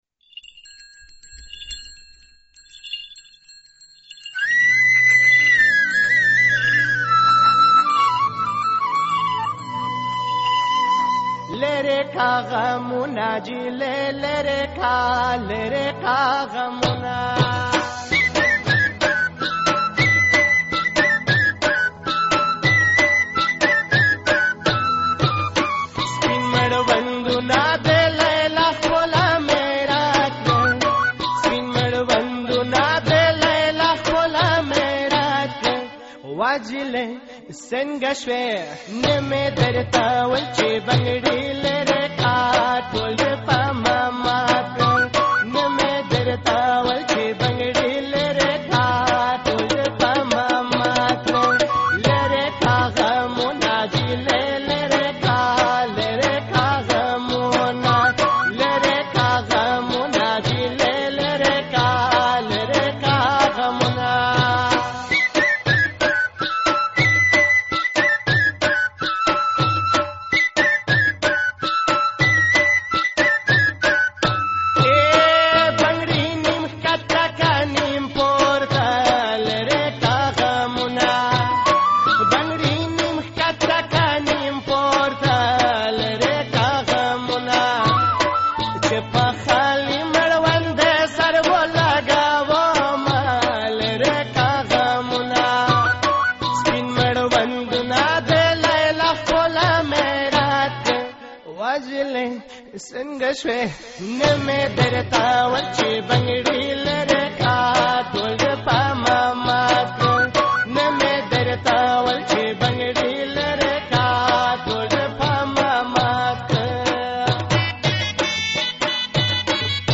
اتڼیزه سندره